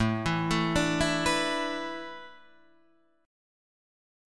Asus2sus4 chord {x 0 0 2 0 0} chord